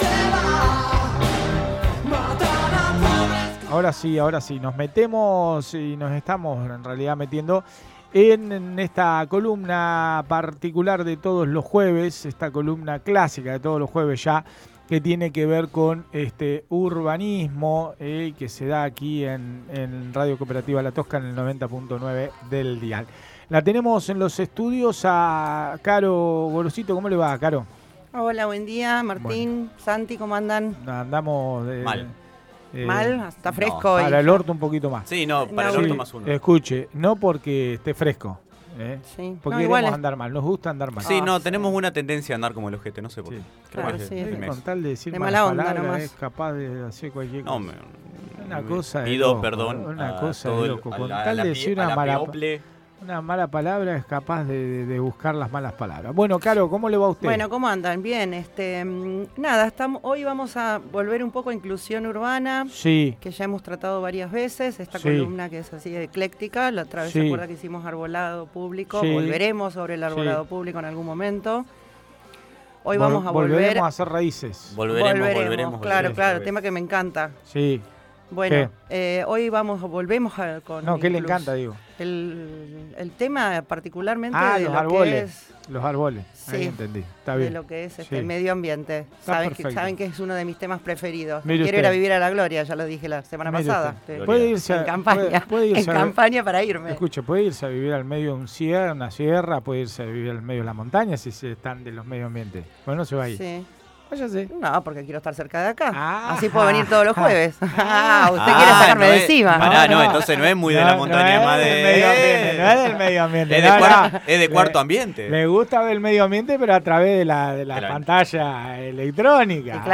En dialogo con el programa radial, menciona que es por distintas problemáticas, entre ellas el precio de los alquileres, servicio o inclusive sus recursos financieros.